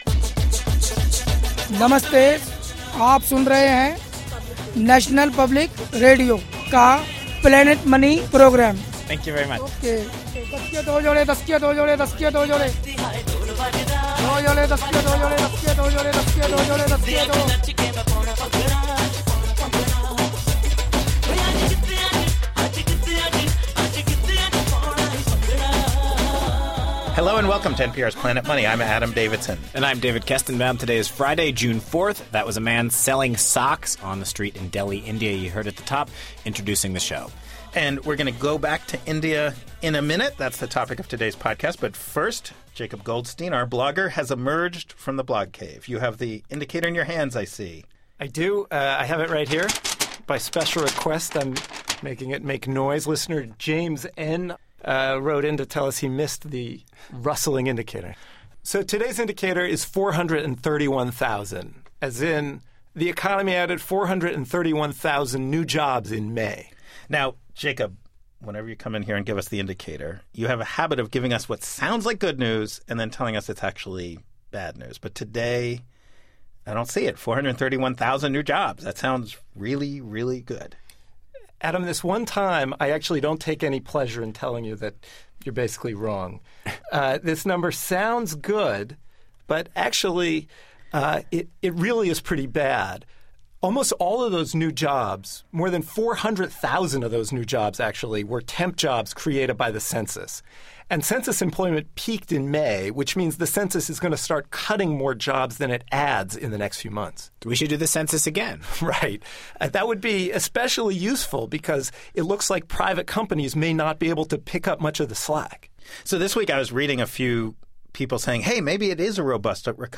Beschreibung vor 15 Jahren In India, there are a handful of billionaires, and 400 million people without electricity. On today's Planet Money, we ask the question: With India's economy growing at 8 percent per year, why are so many people there still so poor? We hear from economists